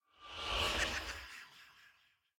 snapshot / assets / minecraft / sounds / mob / vex / idle2.ogg